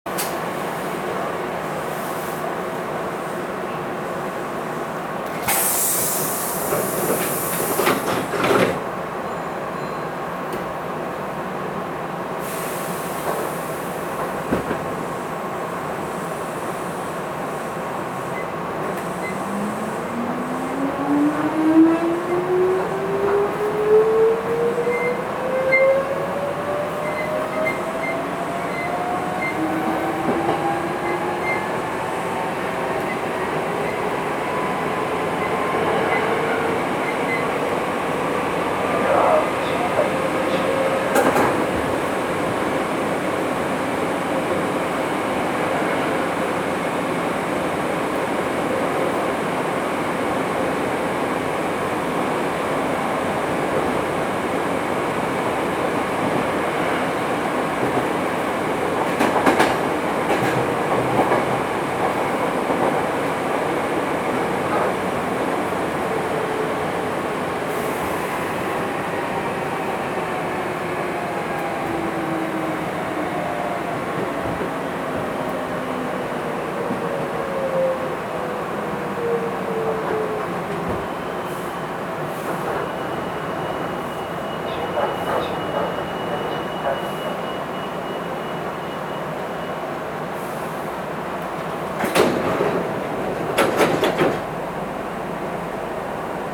走行機器は抵抗制御で、定格140kWのSE607形直流直巻電動機を制御します。
走行音
録音区間：稲野～新伊丹(お持ち帰り)